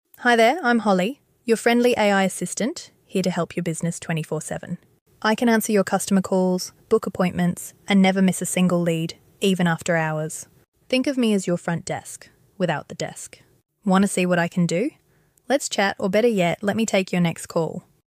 She talks like a human and works like a machine. Now launching for small businesses.🚀 Check our link in bio to book a free demo and meet your newest team member.